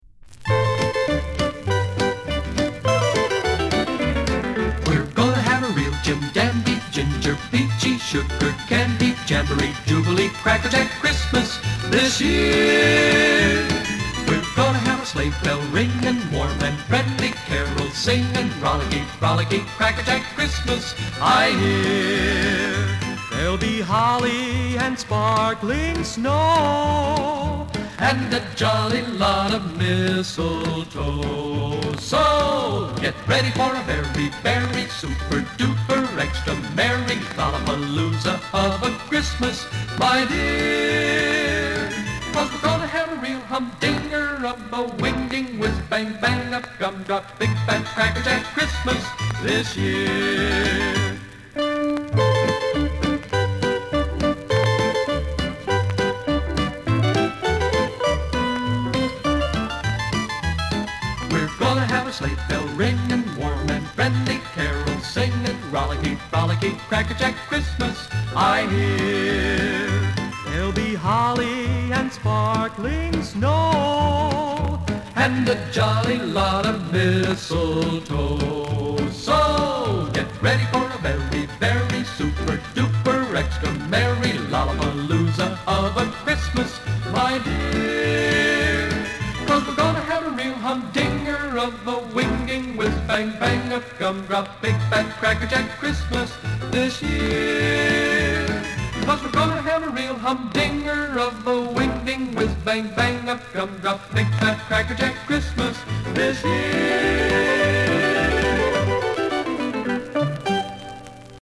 カラー・ワックスと相まって、まさにハッピー・ホリディな気分にさせられる一枚。
ポップ音はいります。